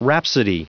Prononciation du mot rhapsody en anglais (fichier audio)
Prononciation du mot : rhapsody